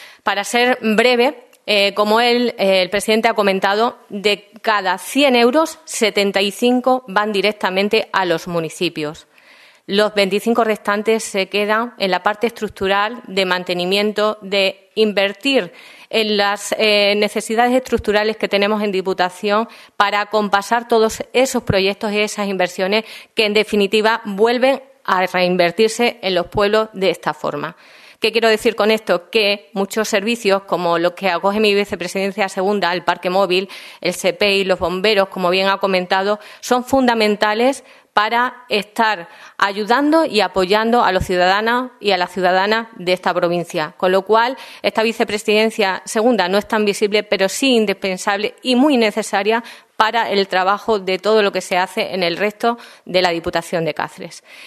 CORTES DE VOZ
Vicepresidenta 2ª_Isabel Ruiz Correyero_Presupuestos provinciales